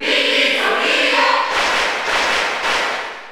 File:Dark Pit Cheer Spanish PAL SSBU.ogg
Category: Crowd cheers (SSBU) You cannot overwrite this file.
Dark_Pit_Cheer_Spanish_PAL_SSBU.ogg